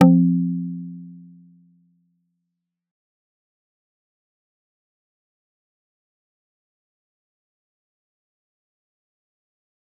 G_Kalimba-F3-f.wav